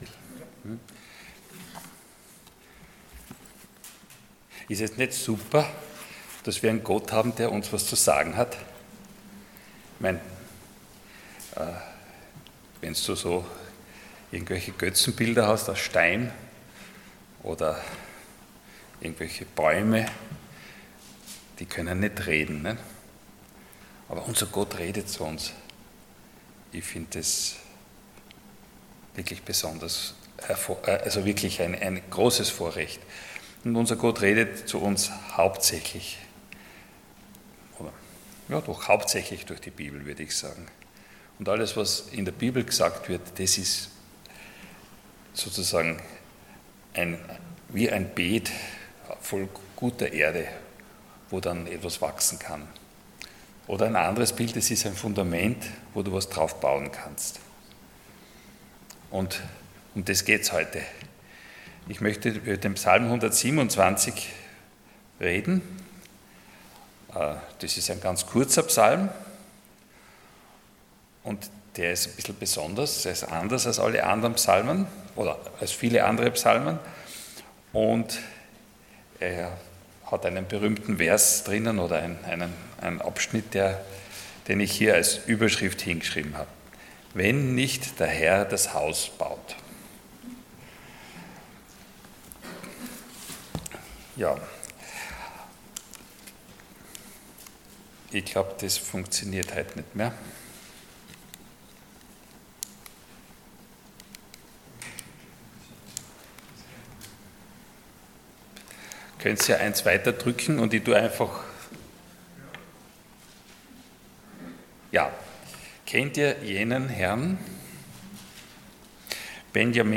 Passage: Psalm 127:1-5 Dienstart: Sonntag Morgen